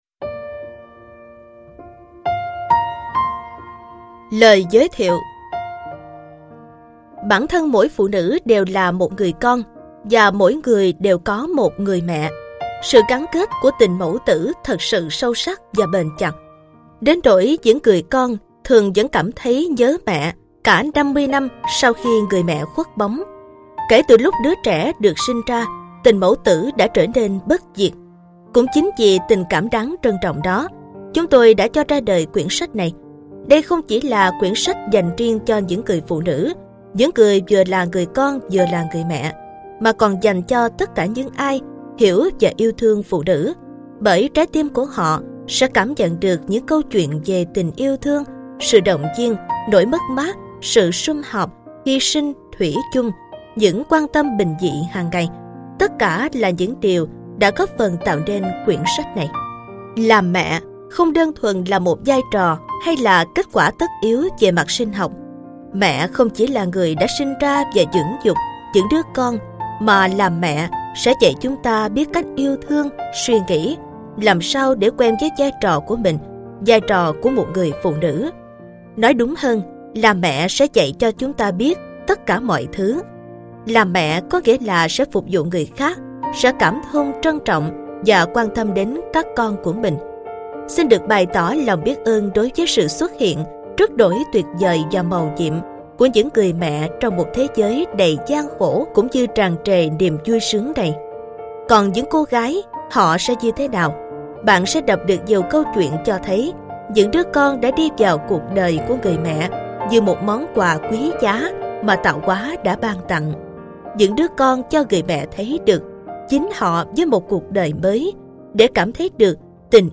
Sách nói Chicken Soup 9 - Vòng Tay Của Mẹ - Jack Canfield - Sách Nói Online Hay